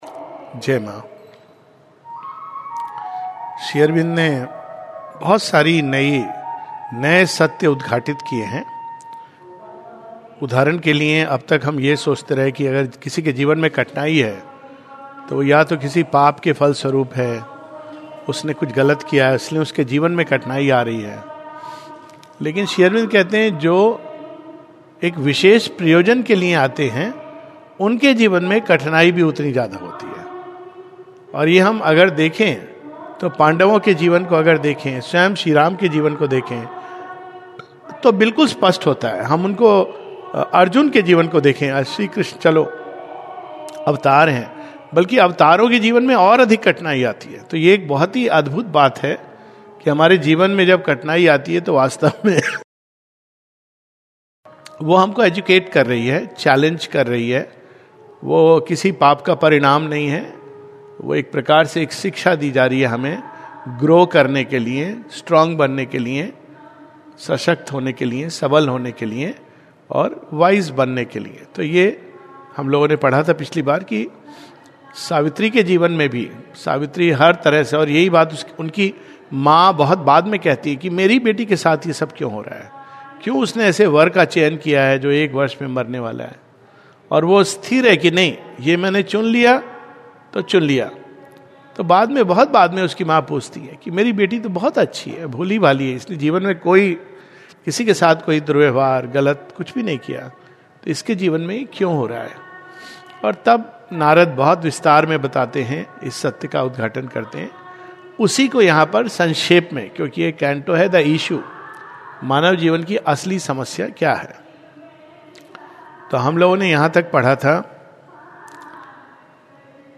(Two types of Humans). A talk